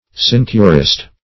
Sinecurist \Si"ne*cu*rist\, n.
sinecurist.mp3